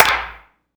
Snares
SNARE.103.NEPT.wav